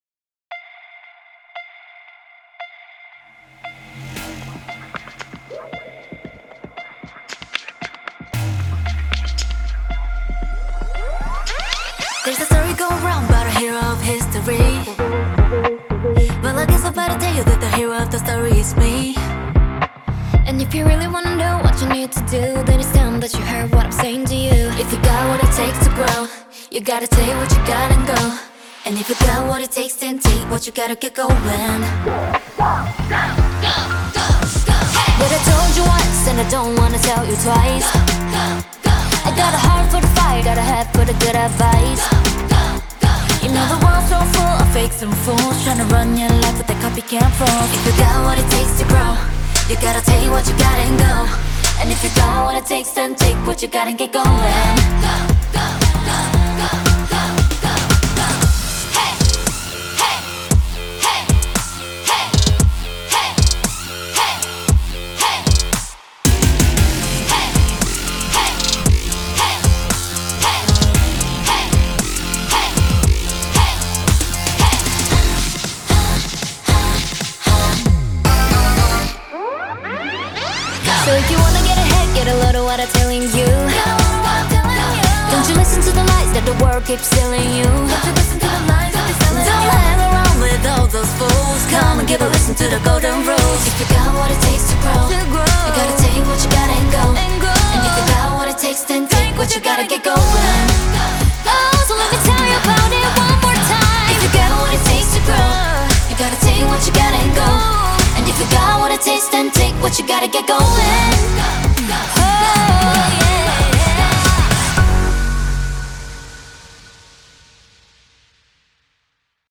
BPM115
Audio QualityPerfect (High Quality)
Comments[FRAGGLE K-POP]